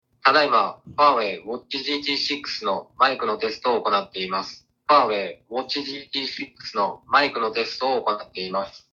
Bluetooth通話にも対応していて、 音質もクリアだ。